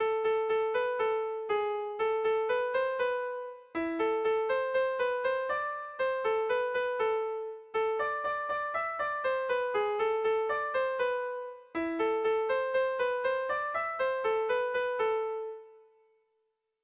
Kontakizunezkoa
Zortziko txikia (hg) / Lau puntuko txikia (ip)
ABDB